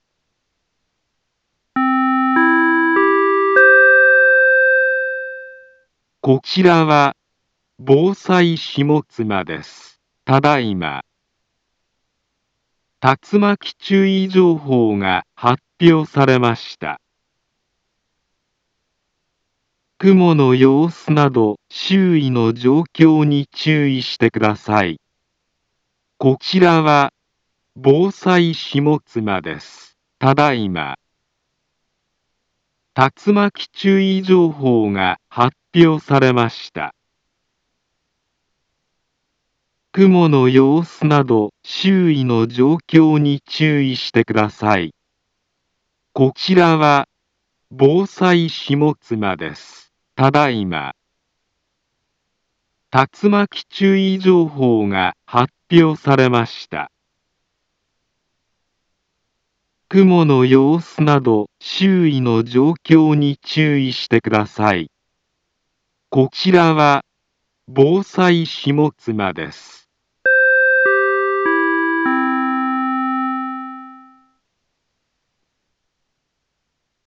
Back Home Ｊアラート情報 音声放送 再生 災害情報 カテゴリ：J-ALERT 登録日時：2021-07-10 19:09:41 インフォメーション：茨城県南部は、竜巻などの激しい突風が発生しやすい気象状況になっています。